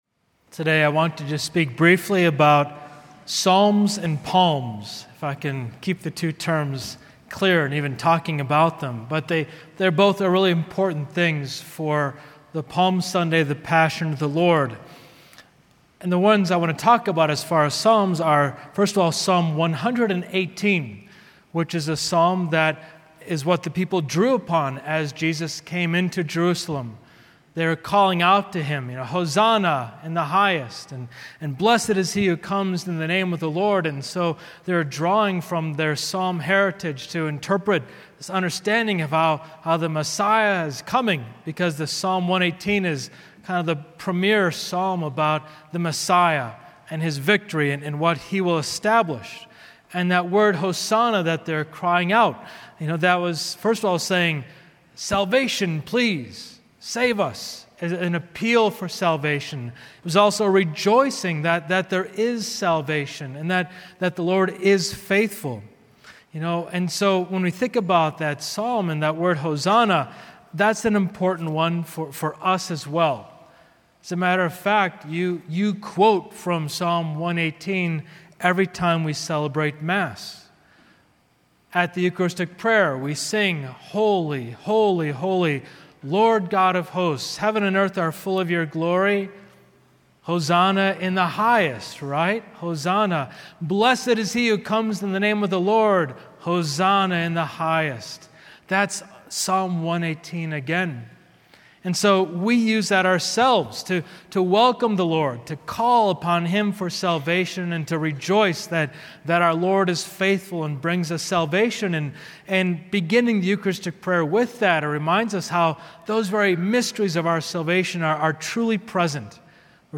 Palm Sunday Homily
palm-sunday-homily-2.mp3